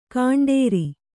♪ kaṇḍēri